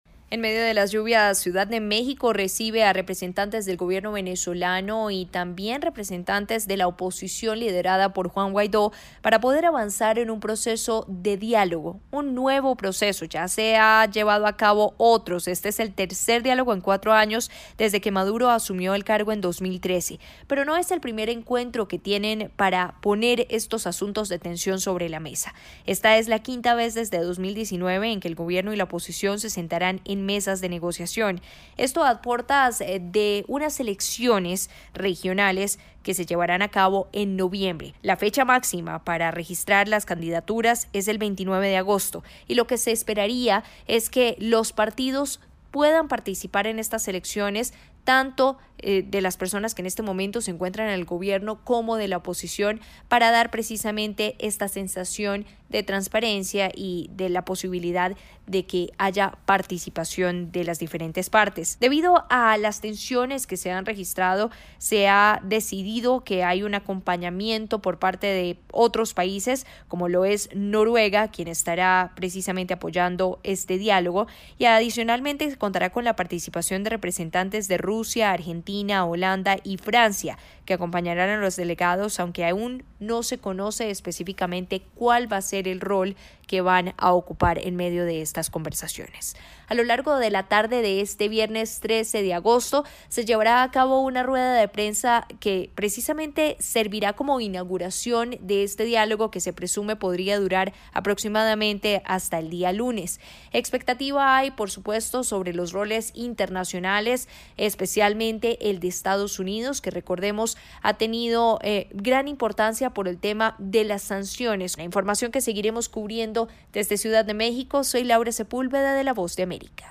Todo listo en México para el inicio de una nueva ronda de diálogo entrre el Gobierno de Nicolás Maduro y la oposición política venezolana facilitado por Noruega. El informe